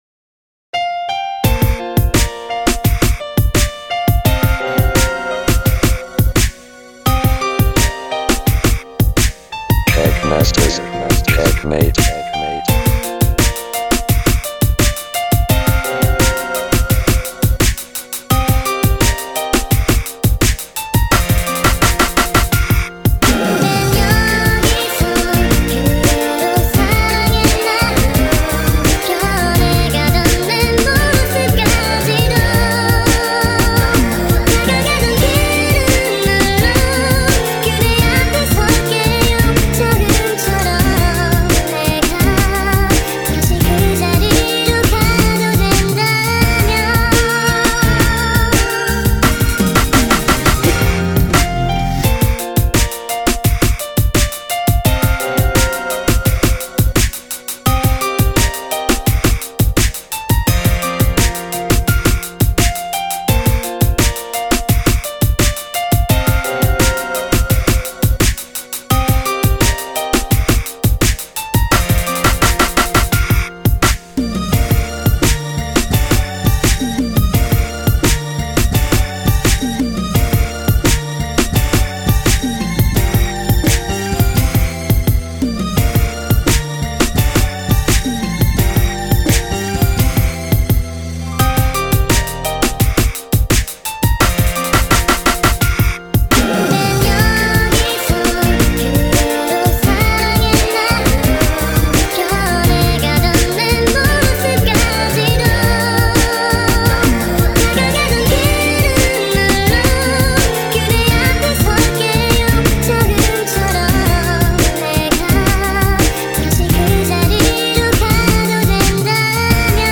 ㅋㅋ일단은 각오하고 아이디어까지 샘플링한 통샘하나 투척합니다 ㅋ